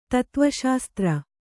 ♪ tatva śastra